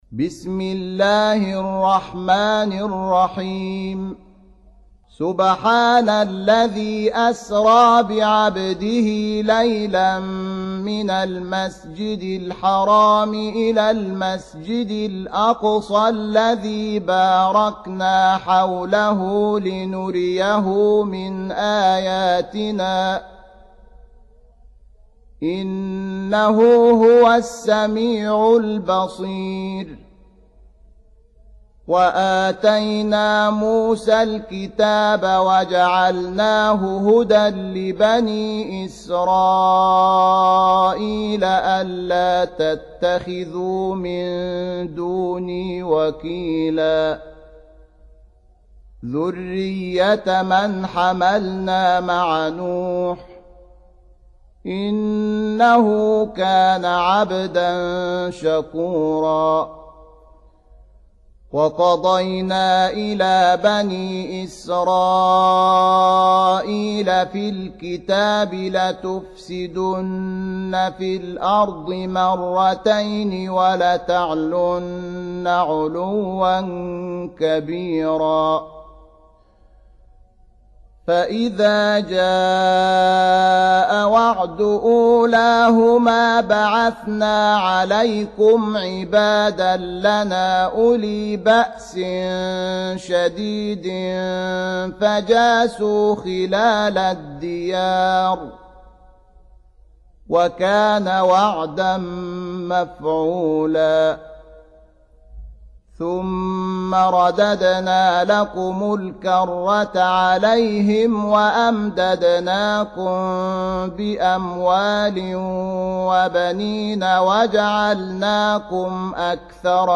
17. Surah Al-Isr�' سورة الإسراء Audio Quran Tarteel Recitation
Surah Sequence تتابع السورة Download Surah حمّل السورة Reciting Murattalah Audio for 17.